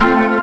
B3 FMAJ 1.wav